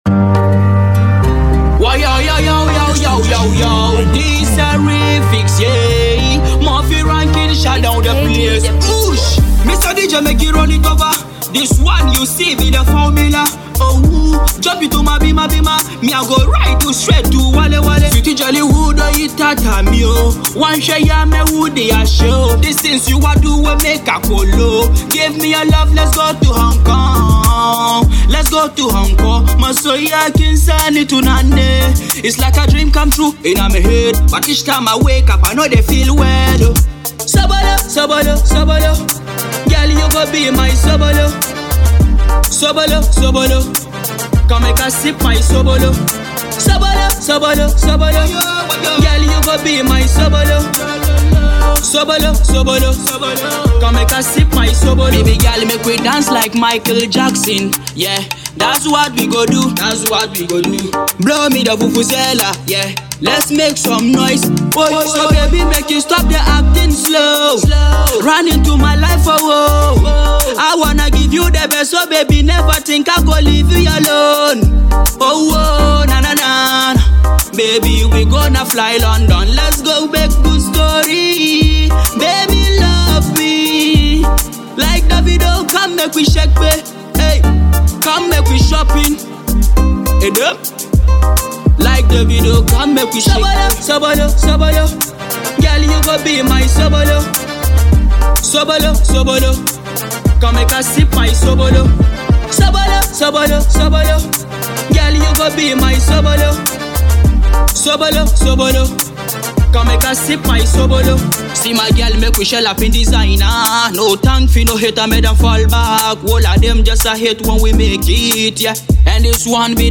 This is a massive tune you should never miss.